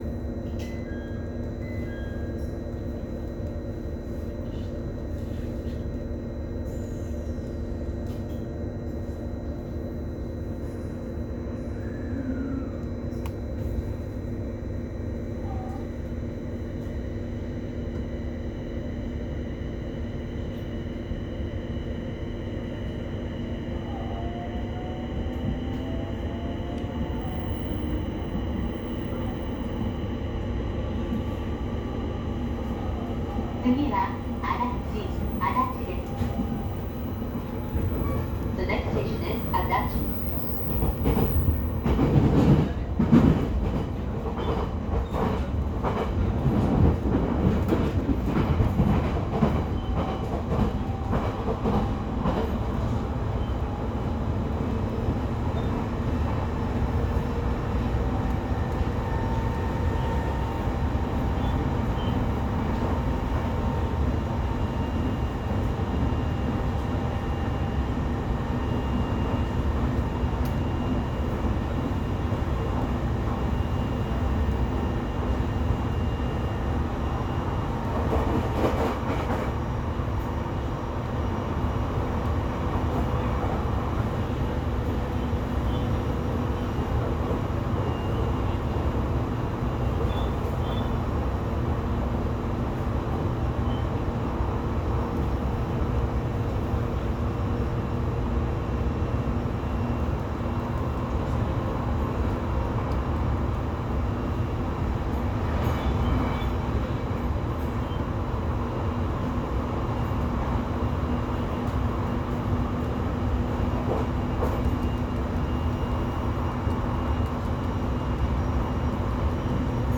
・E721系走行音
【東北本線】松川→安達
半自動扱い故いきなり出発するところから始まる音声も含まれます。聞き慣れない音ですが一応三菱製との事。